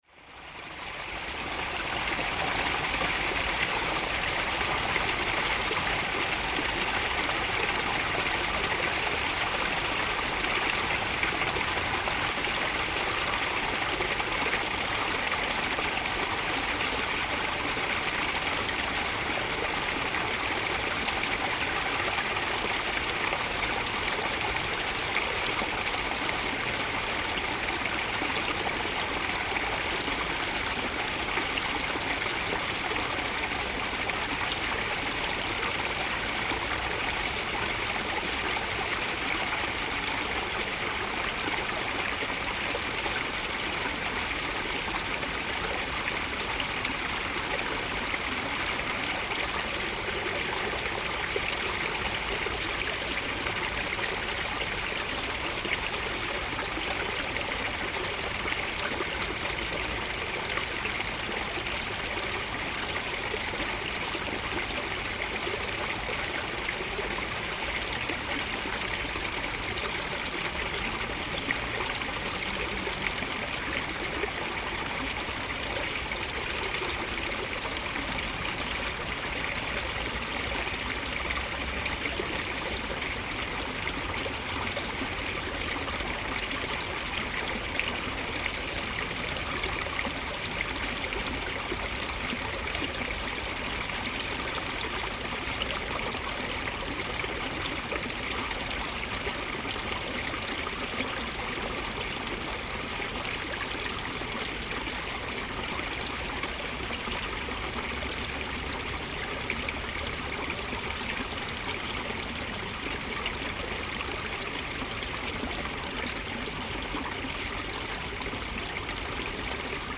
Binaural layered stream, Vicenza
A binaural recording of a weir stream that runs at two different levels, in Parco Querini in the city centre of Vicenza, Italy.